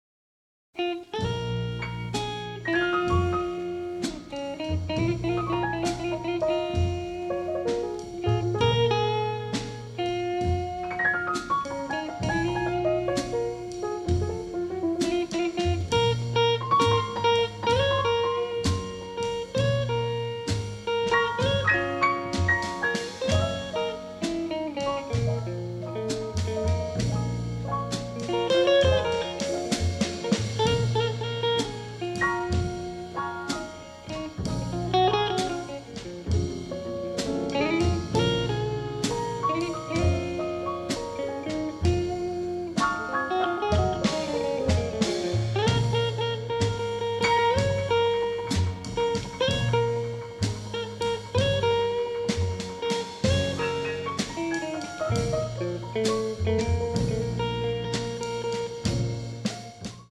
diegetic jazz and 1950s-styled dance tunes